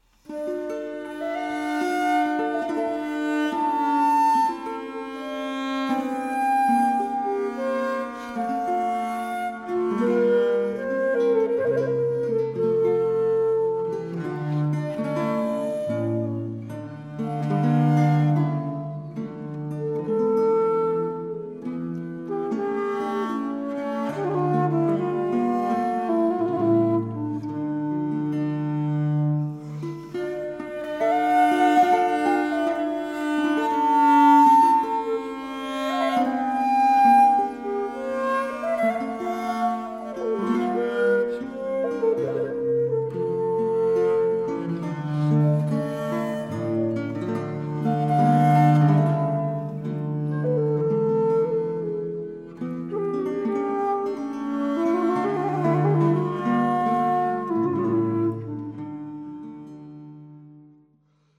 Pièces inédites pour flûte
flûte traversière
viole de gambe
archiluth, guitare
Enregistré en septembre 2024 à la Ferme de Villefavard.